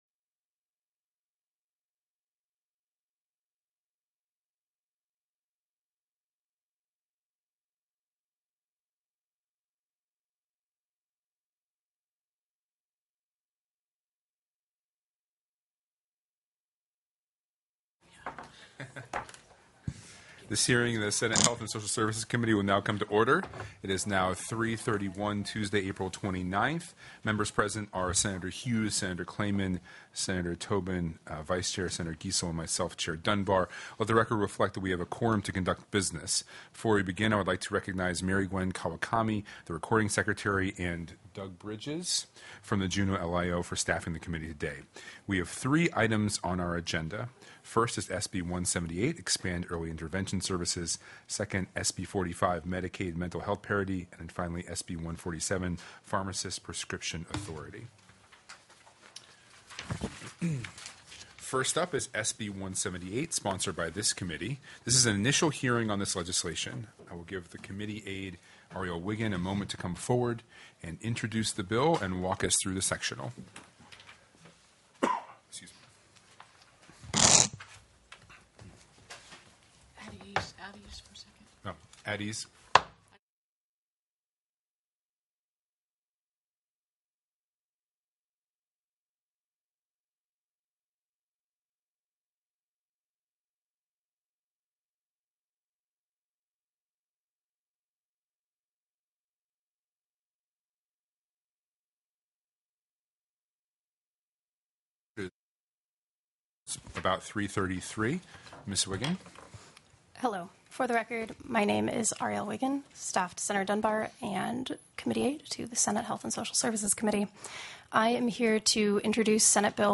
04/29/2025 03:30 PM Senate HEALTH & SOCIAL SERVICES
The audio recordings are captured by our records offices as the official record of the meeting and will have more accurate timestamps.